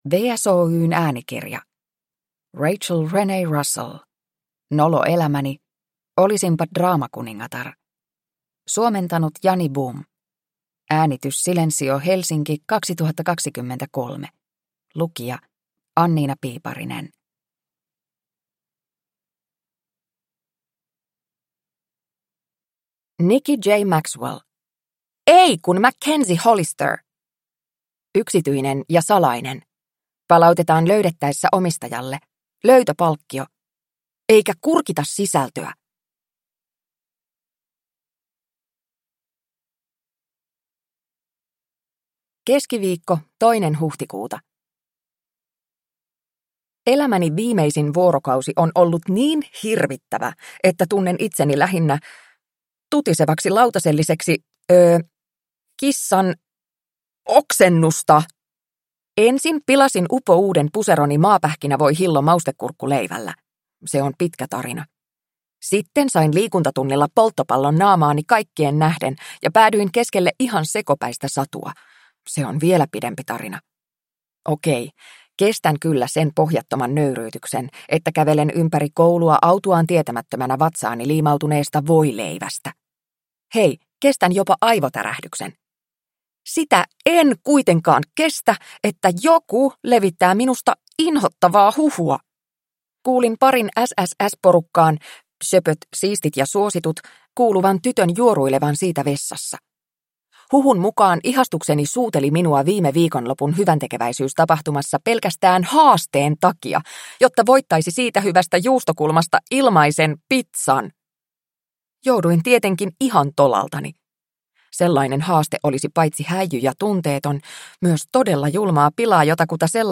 Nolo elämäni: Olisinpa draamakuningatar – Ljudbok